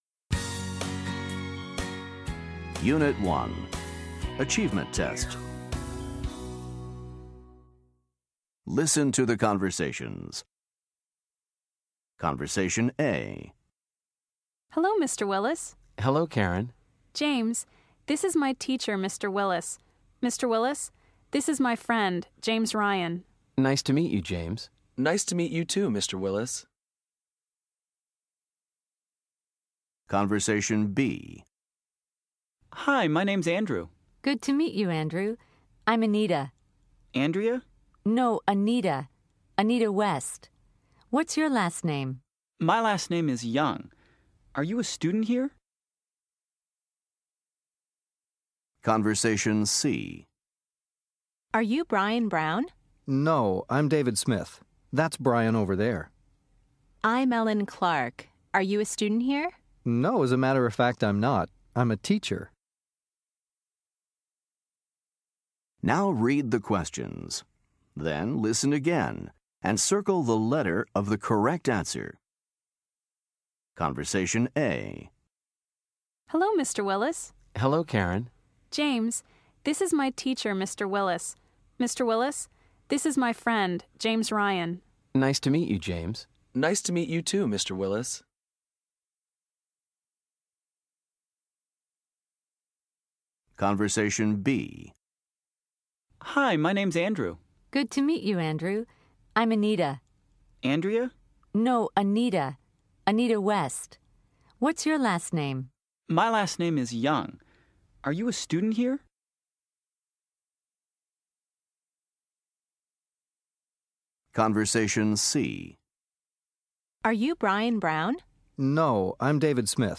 Listen to the conversations. Then choose the correct answer for each question.